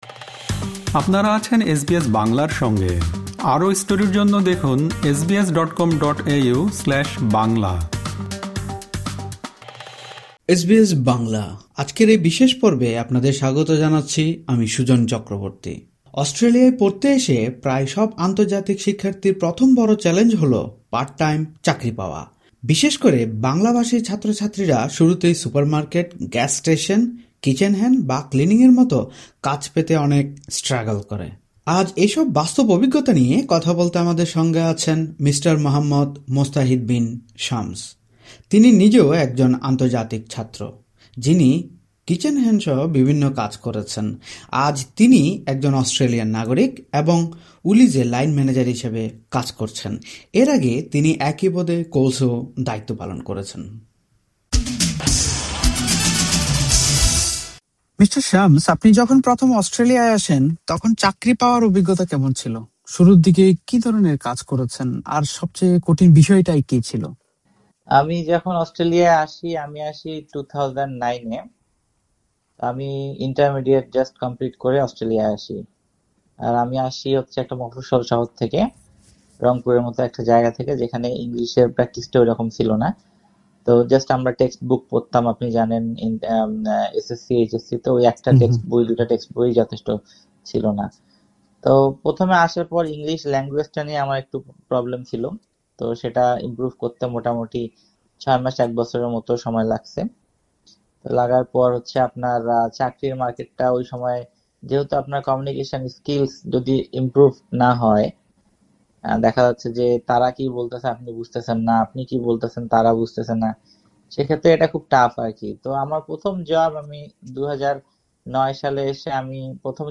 এই সাক্ষাৎকারে তার ব্যক্তিগত সংগ্রামের অভিজ্ঞতা এবং সফল হওয়ার মূলমন্ত্রগুলো তুলে ধরেছেন।